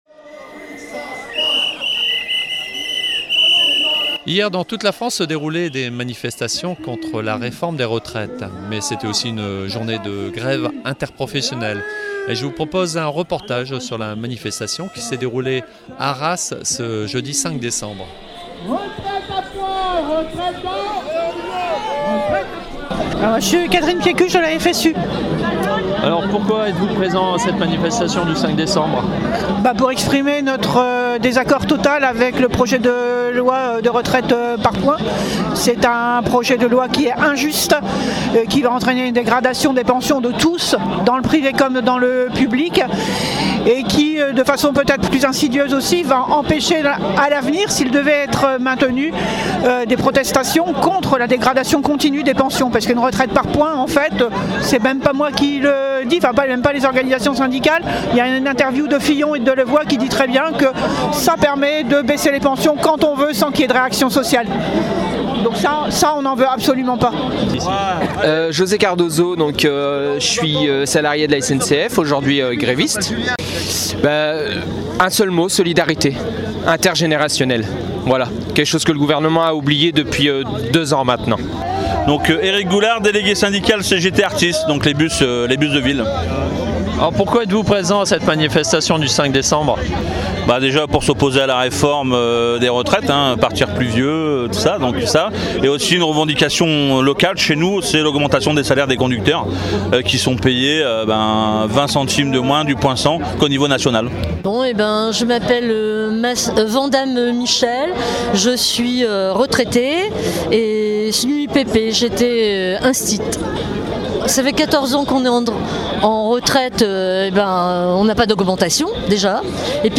Reportage réalisé lors de la manifestation "Grève Générale !" du jeudi 5 décembre à Arras.
49MAnifestation Retraite.mp3